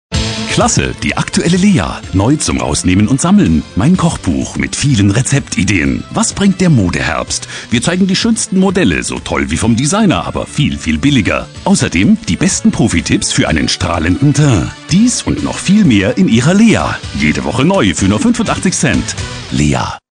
Kein Dialekt
Sprechprobe: Sonstiges (Muttersprache):
german voice over artist.